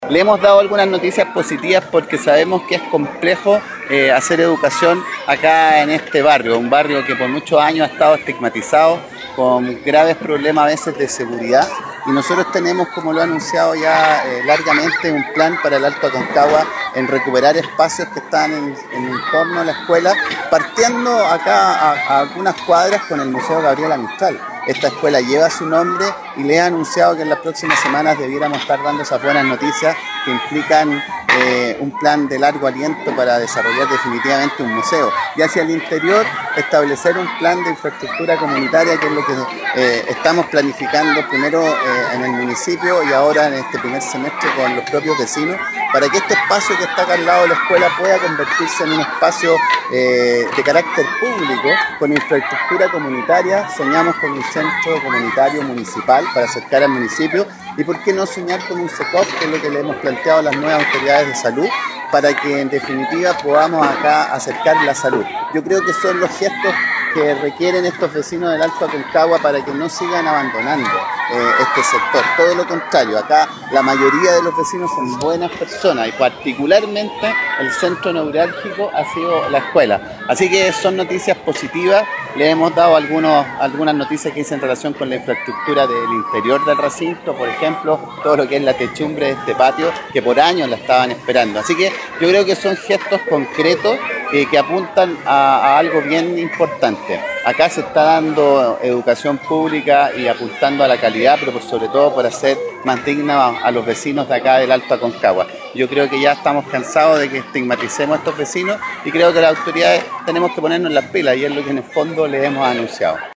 Alcalde-Manuel-Rivera-aniversario-escuela-Gabriela-Mistral1.mp3